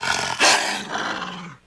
Sound / sound / monster / wolf / attack_2.wav